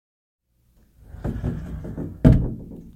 描述：打开和关闭一个金属罐。
Tag: 开启 关闭 Tascam的 金属 现场记录 DR-40